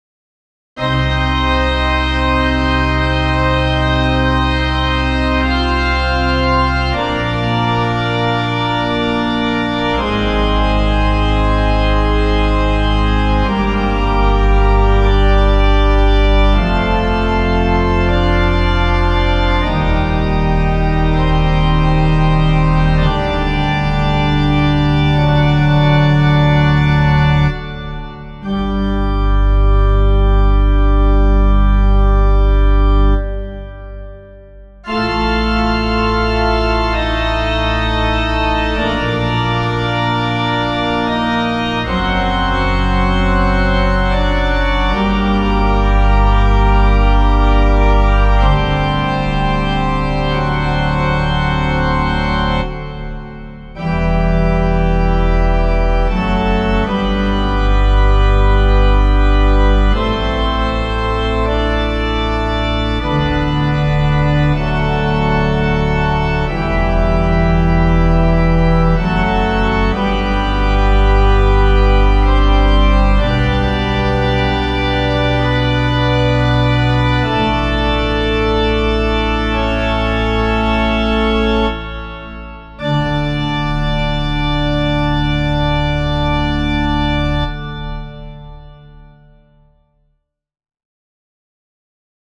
Klassische Orgelklänge